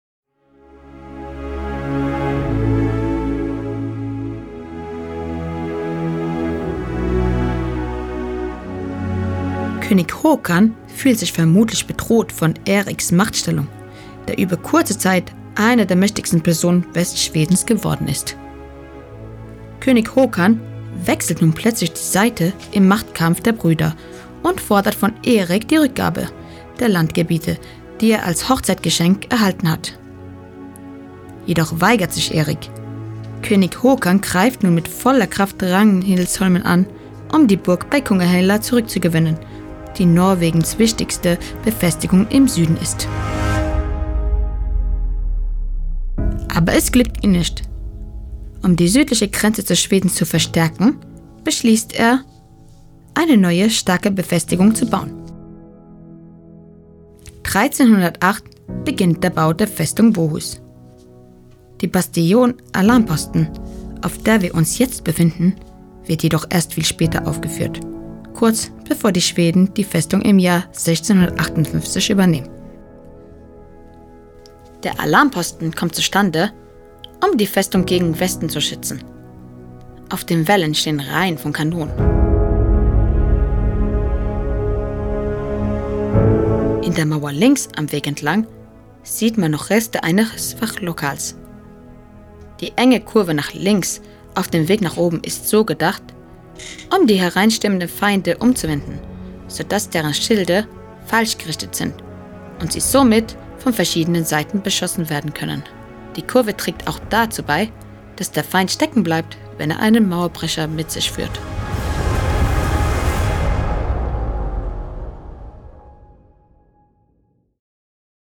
Erzählerstimme
Mit diesem Audioguide tauchst du ein in die dramatische Geschichte der Festung Bohus – von mittelalterlichen Machtspielen und königlichen Hochzeiten bis hin zu blutigen Belagerungen, Gefängniszellen und Hexenprozessen.